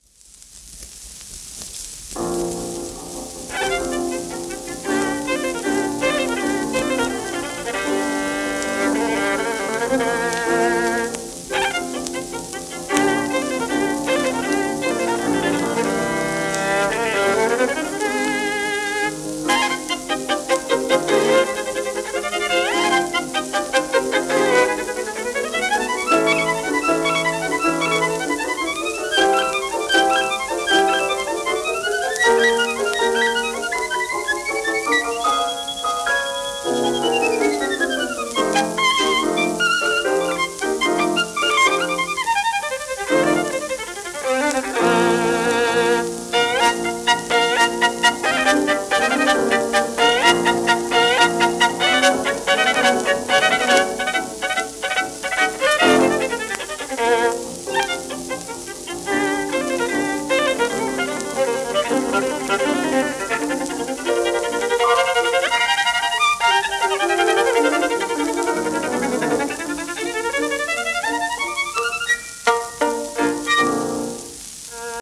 w/ピアノ
盤質B+ *小キズ多め（キズはやや音に出る部分あり）、薄いスレ
1928年録音